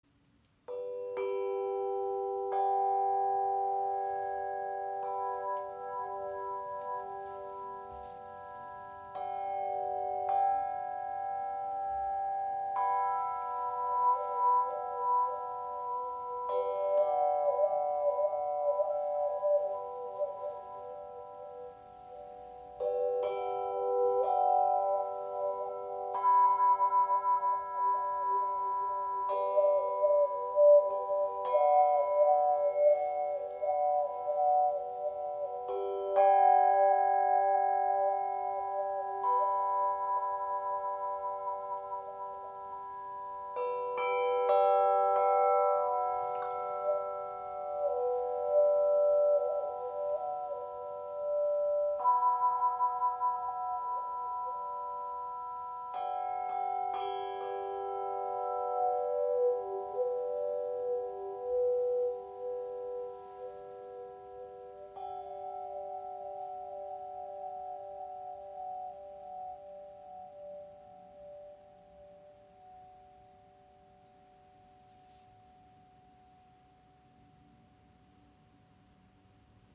Die Wayunki ist ein vielseitiges Klanginstrument, das mit sanften Schwingungen tiefe Entspannung, Meditation und Klangheilung unterstützt.
Wayunki in 440 Hz | Nr.  2 | F♯ Moll | Vielstimmiger Wah Wah Effekt
Wayunki in F♯ Minor – Sanft und tief
Gestimmt auf F♯ – A – C♯ – F♯ – A – C♯, vielstimmiger Wah Wah Effekt mit ausdrucksvollem Klangbild mit sanften Obertönen.